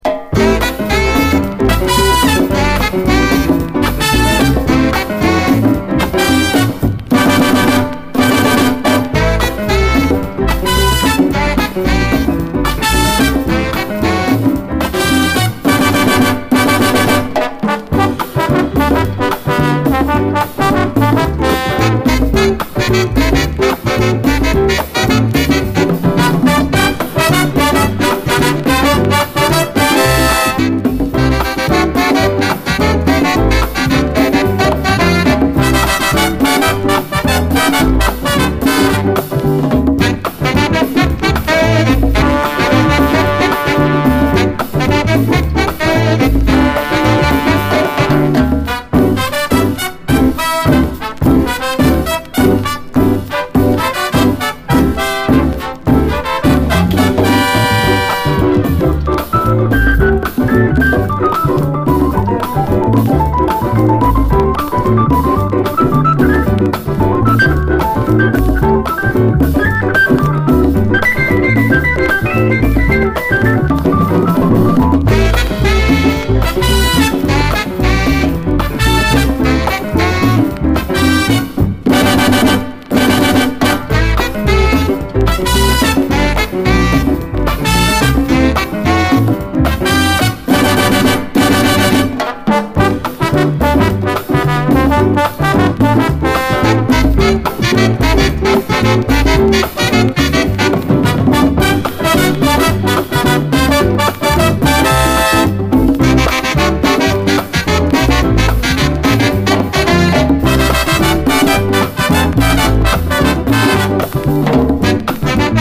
LATIN
喜び爆発のインスト・ラテン・ダンサー
繊細かつ大胆なブラス・アレンジに興奮！